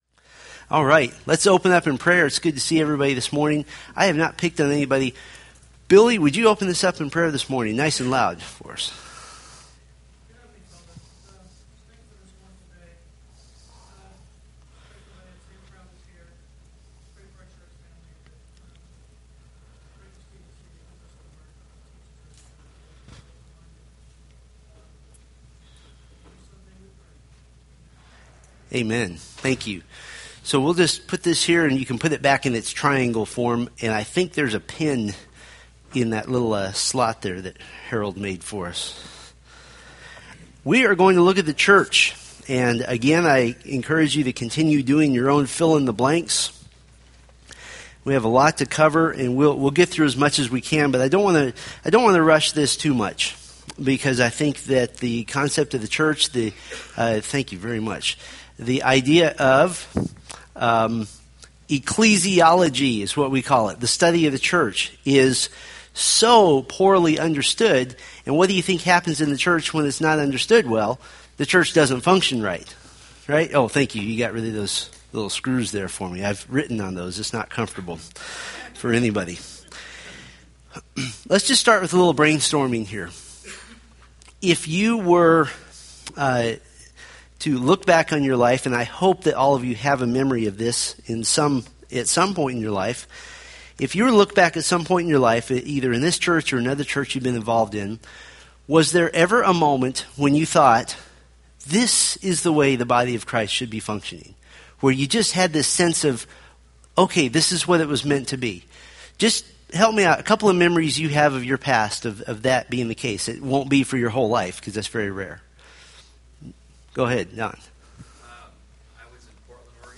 Date: Aug 18, 2013 Series: Fundamentals of the Faith Grouping: Sunday School (Adult) More: Download MP3